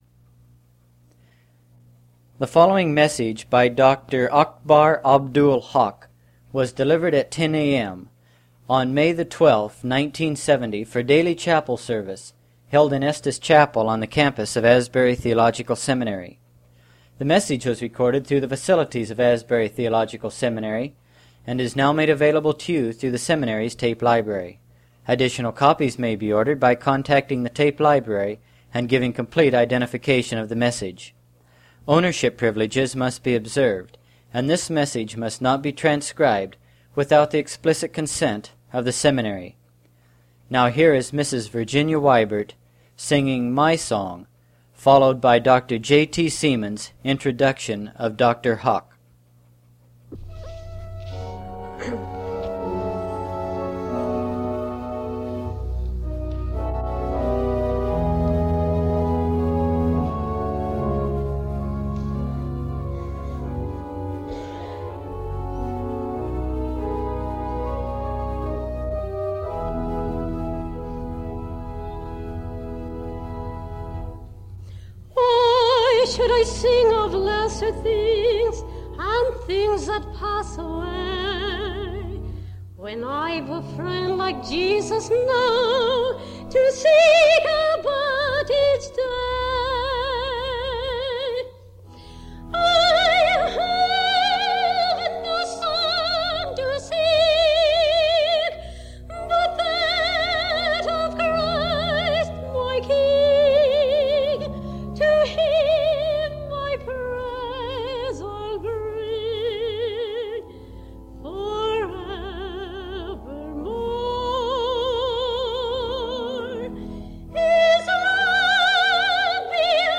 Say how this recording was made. Chapel services, 1970